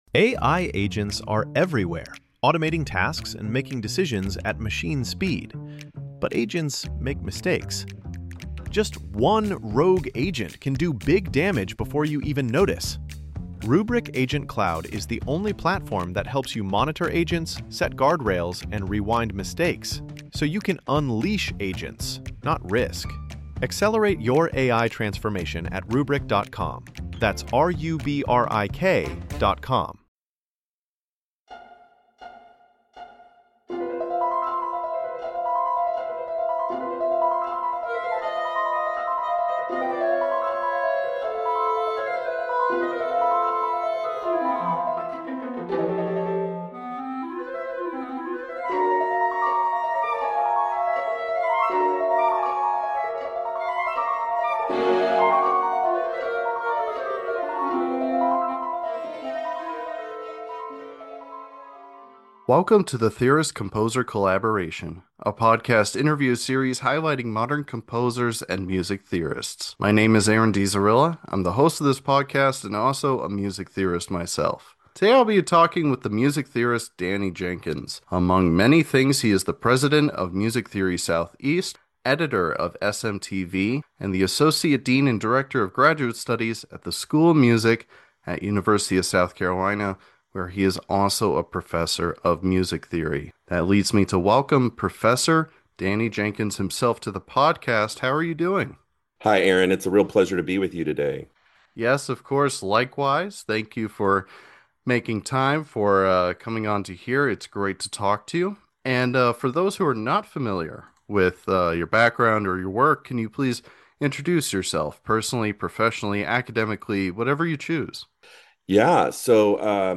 The Theorist Composer Collaboration is a podcast interview series highlighting modern composers and music theorists. With guests ranging from freelance composers, graduate students, and tenured professors, the TCC is a space of discourse and discussion between the similar but the often separated fields of music theory and composition, bringing together modern musical analysis and practice.